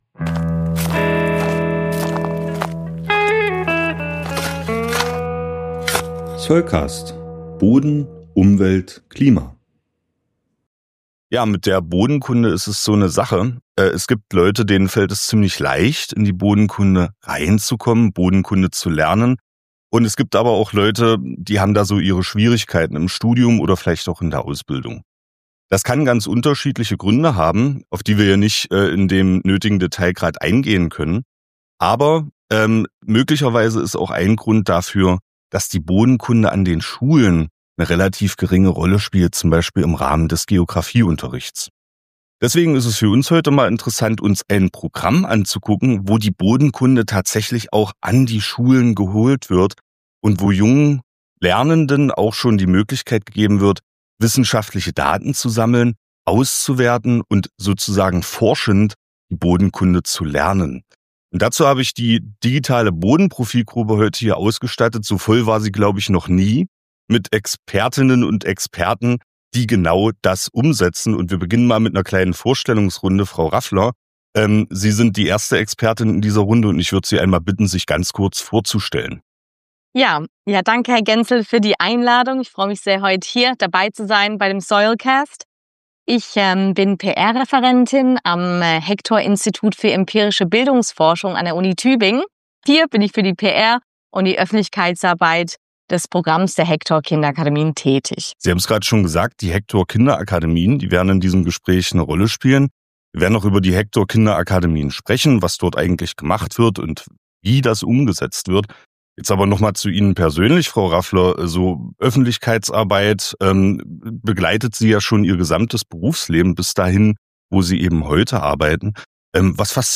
In diesem Interview besprechen wir, wie das gelingt, wie erfolgreich der Kurs ist und wie er angenommen wird.